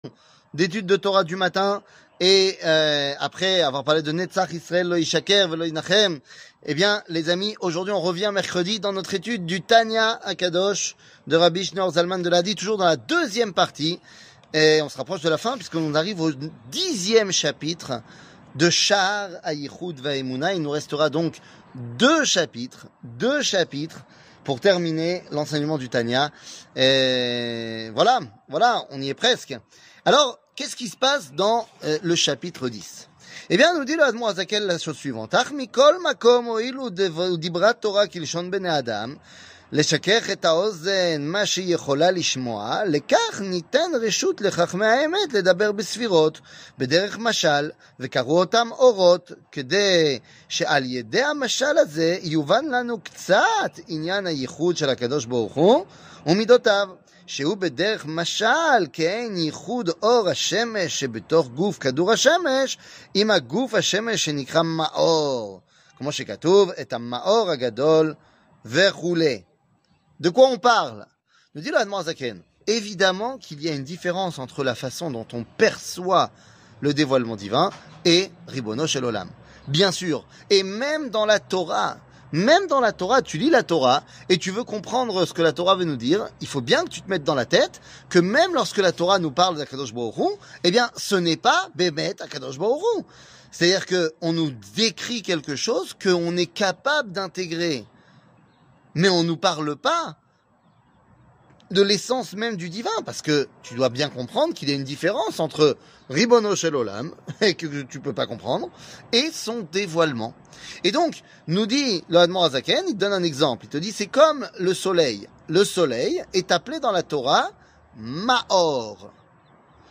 Tania, 64, Chaar Ayihoud Veaemouna, 10 00:05:59 Tania, 64, Chaar Ayihoud Veaemouna, 10 שיעור מ 18 אוקטובר 2023 05MIN הורדה בקובץ אודיו MP3 (5.48 Mo) הורדה בקובץ וידאו MP4 (9.26 Mo) TAGS : שיעורים קצרים